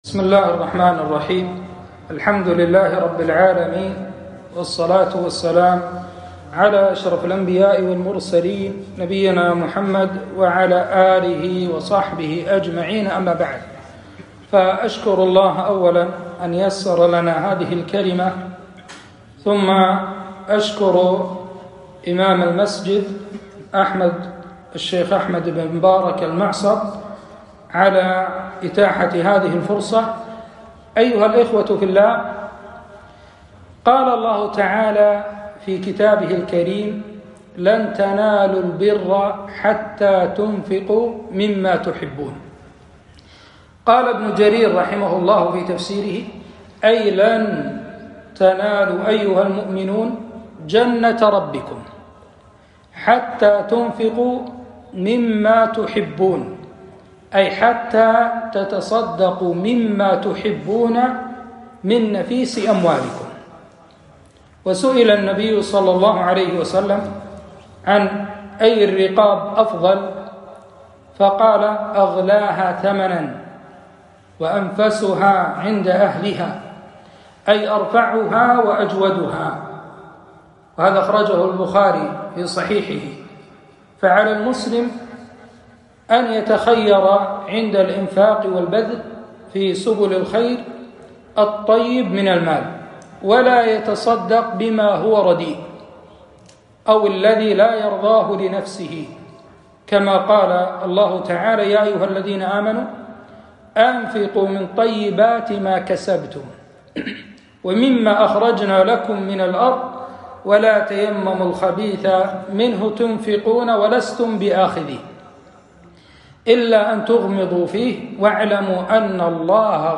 محاضرة - (لن تنالوا البر حتى تنفقوا مما تحبون)